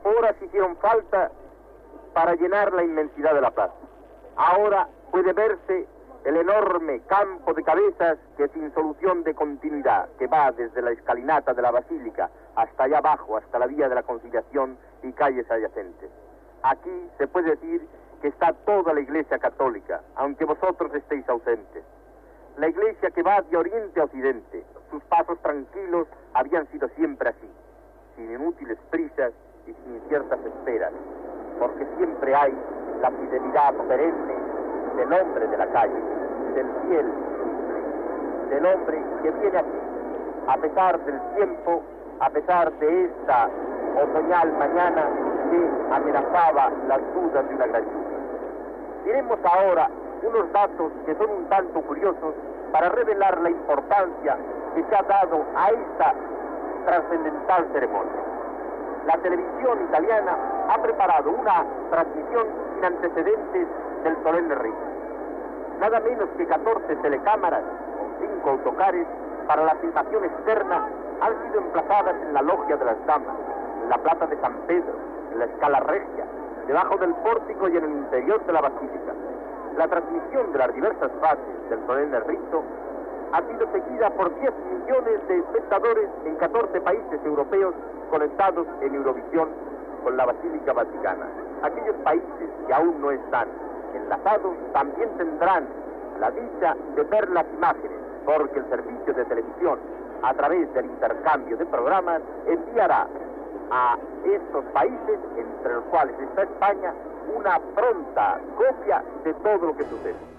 Transmissió des de la Ciutat del Vaticà en el dia de l'elecció del Sant Pare Joan XXIII.
Comentari de l'ambient a la plaça de Sant Pere i dels mitjans de comunicació presents.
Informatiu